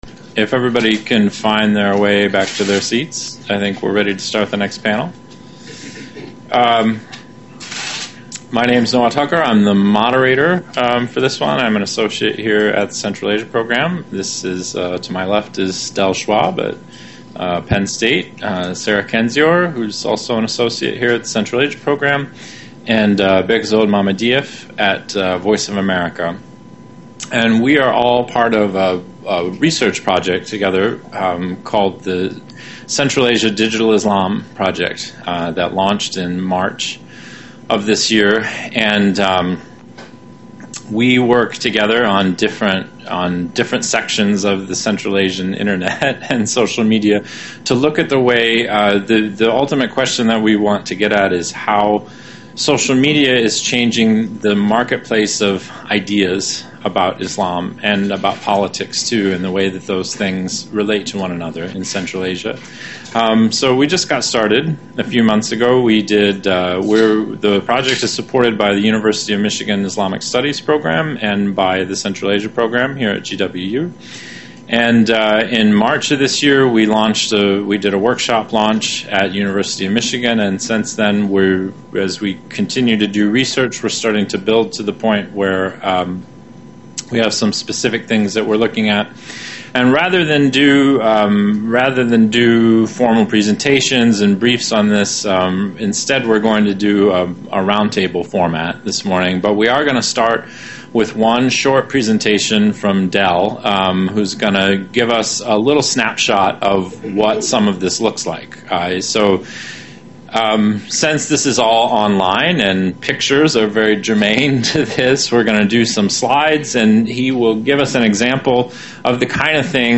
Roundtabe on Digital Islam.
Questions and answers.